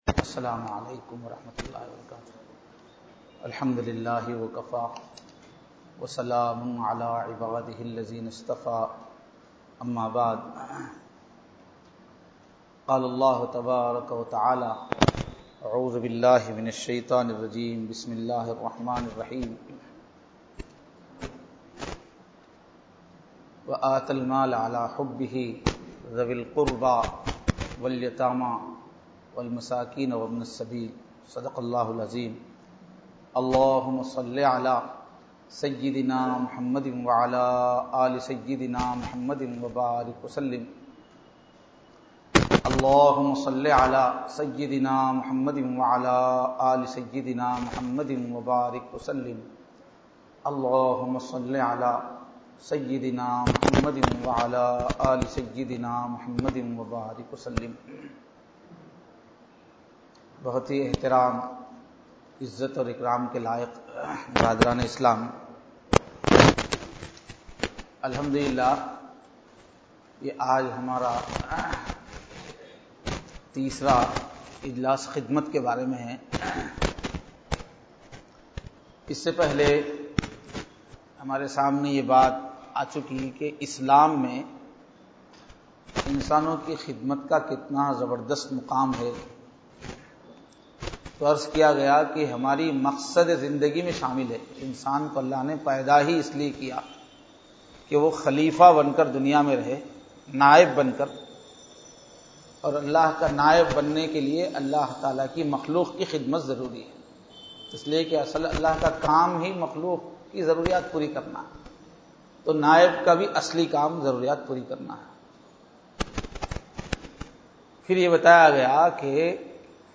Bayanath